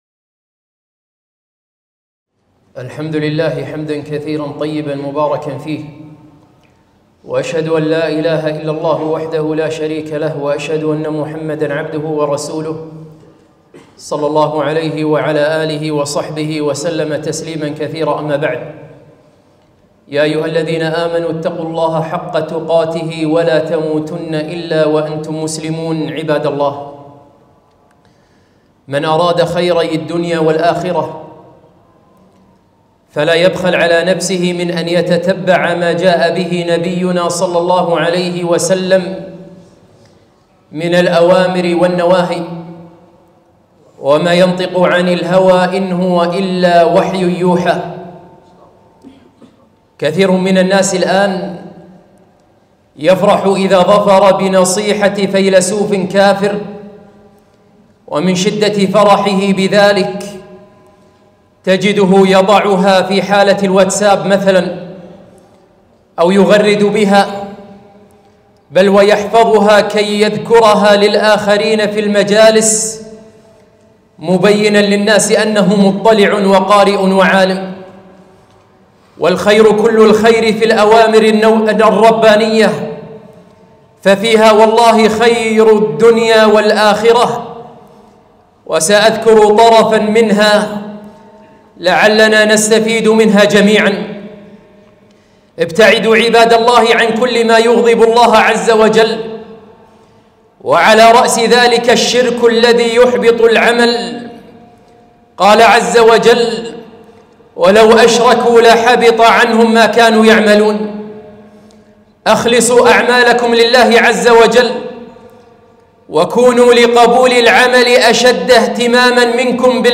خطبة - نصائح